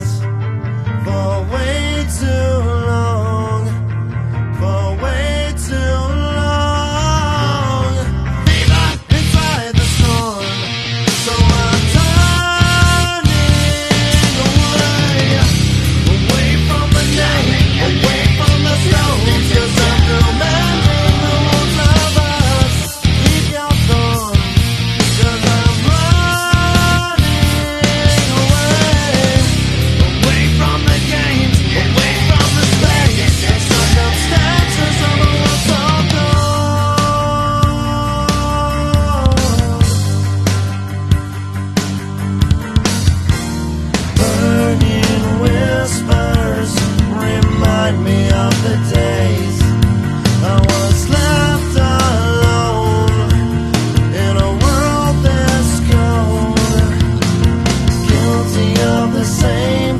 my race car. ...we raced sound effects free download
...we raced Mp3 Sound Effect my race car. ...we raced at Southern Oregon Speedway and Yreka Speedway..